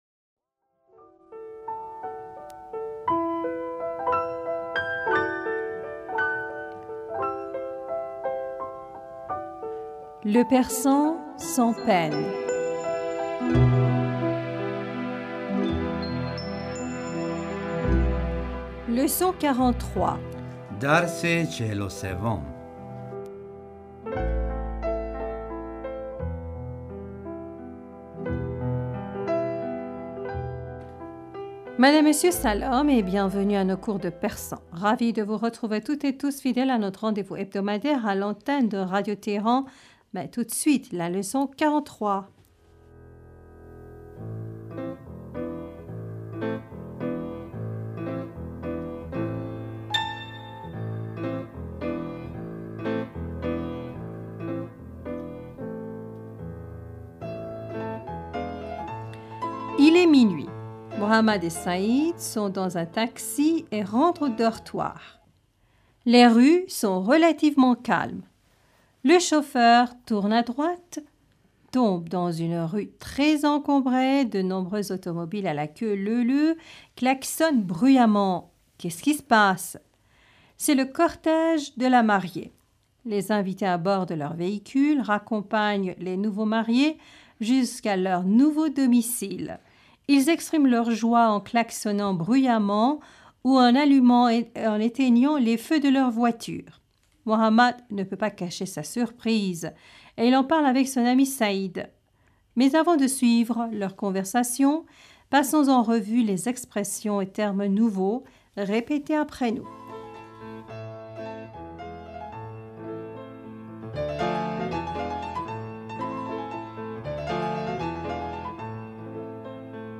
Madame, Monsieur, Salam et bienvenus à nos cours de persan.